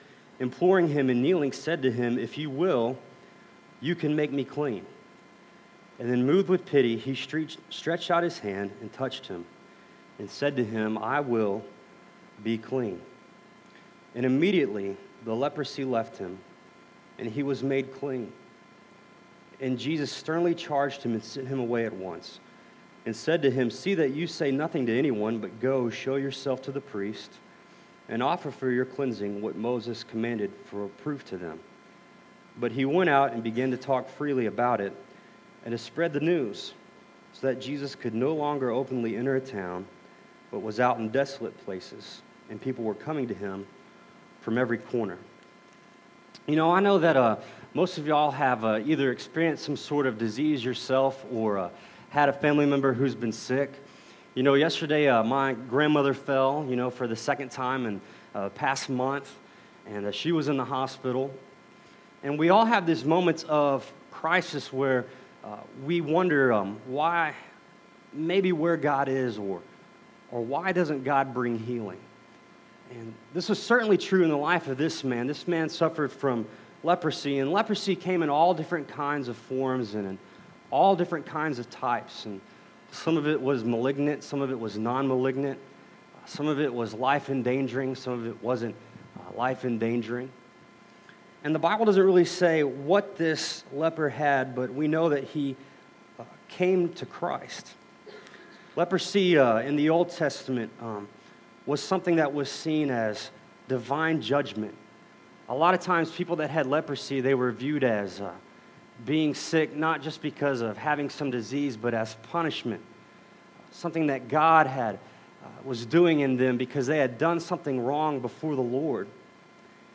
Audio begins a little ways into the reading of scripture.